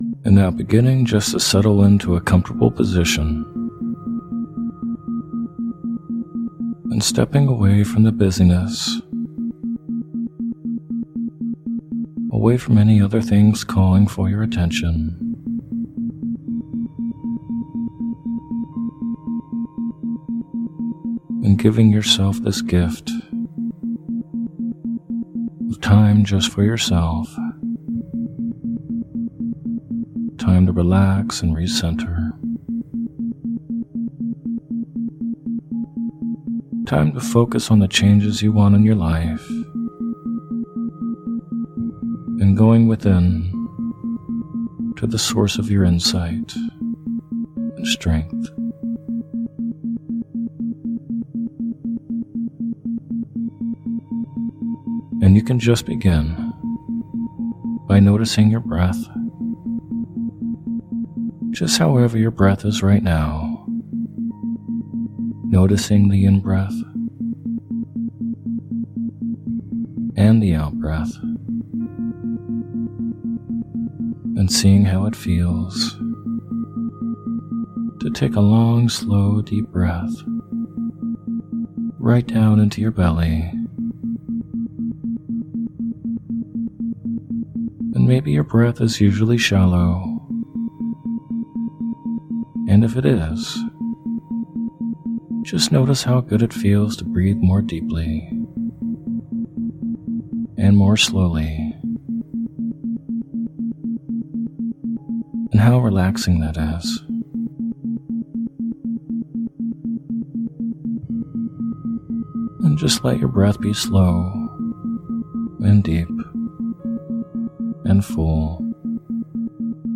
Releasing Addictions and Bad Habits With Isochronic Tones
In this hypnosis audio, you’ll be guided to release bad habits and addictions.